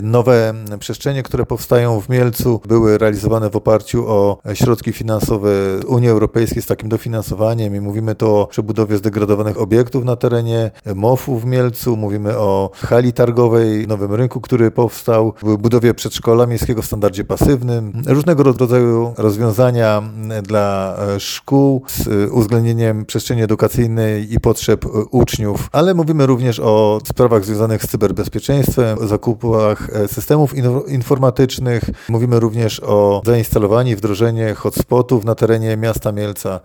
Z kulturą po Europie, pod takim hasłem w niedzielę w Mielcu w ramach Dni Otwartych Funduszy Europejskich, będzie mieć miejsce wydarzenie , które ma pokazać, jak wiele ciekawych i potrzebnych projektów udało się zrealizować dzięki Funduszom Europejskim. Z takich skorzystało także i miasto Mielec, zaznacza wiceprezydent Mielca, Paweł Pazdan.